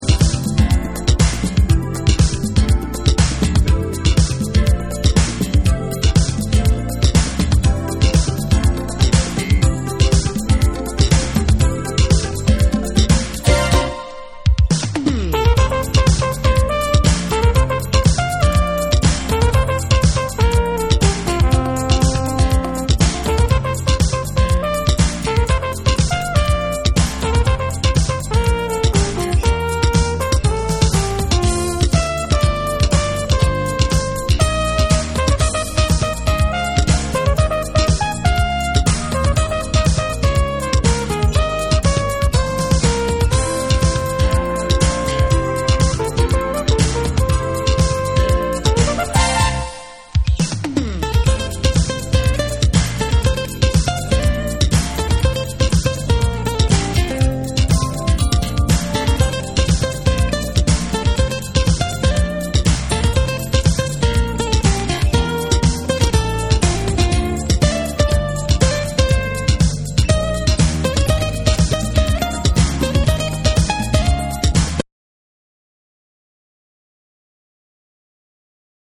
WORLD / AFRICA / TECHNO & HOUSE